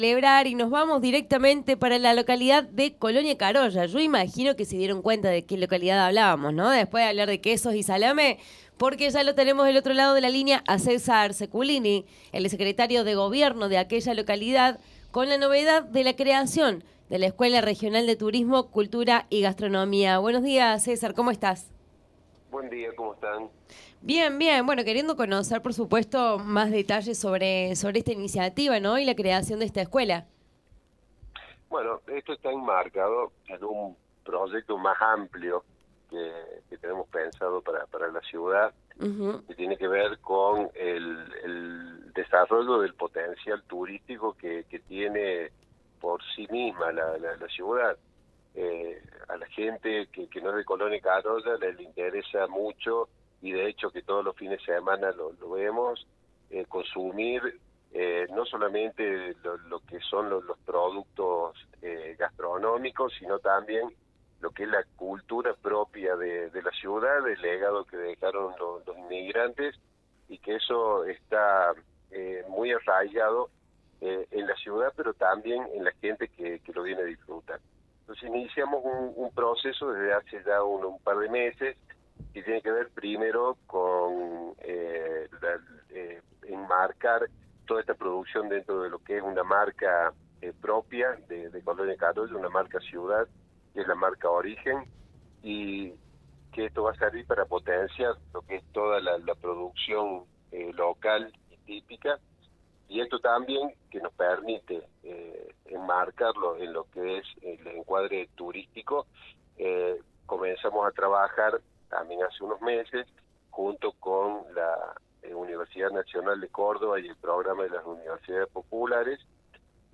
ENTREVISTA A CÉSAR SECULINI, SECRETARIO DE GOBIERNO DE COLONIA CAROYA